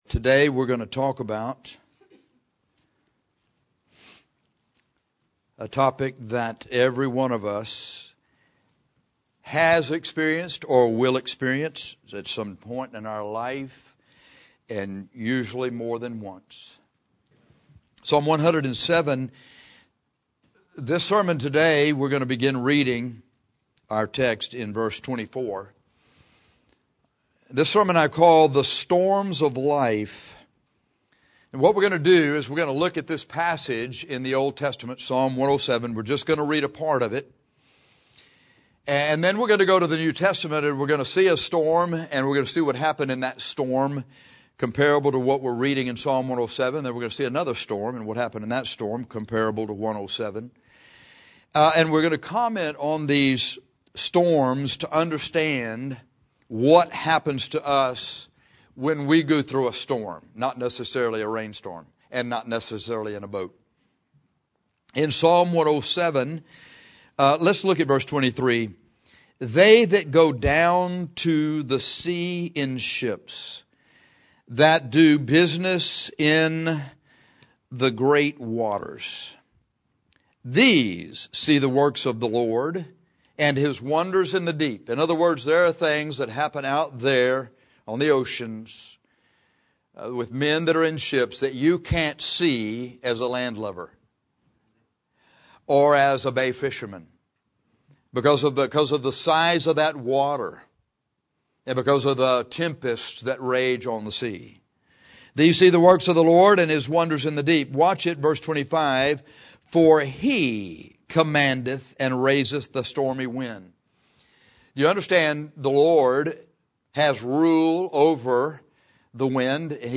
This sermon is about the storms of life.